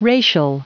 Prononciation du mot racial en anglais (fichier audio)
Prononciation du mot : racial